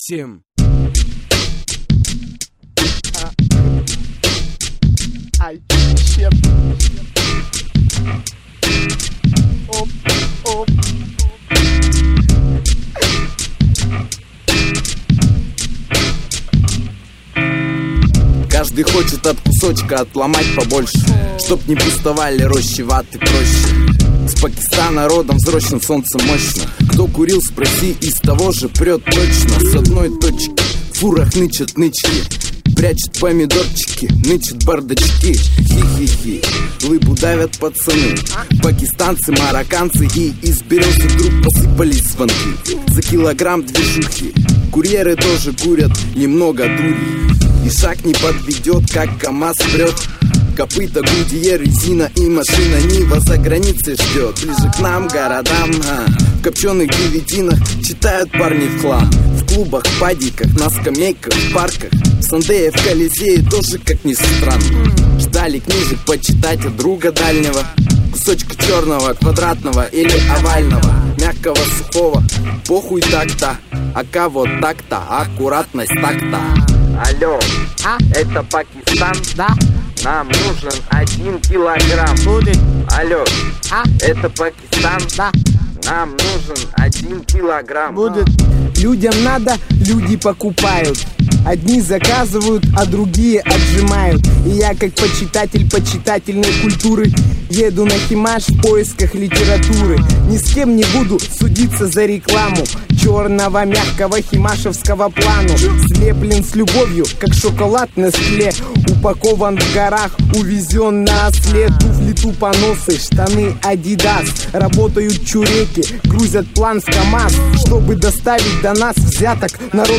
Главная » Каталог музыки » Русский рэп
Данная песня находится в музыкальном жанре Русский рэп.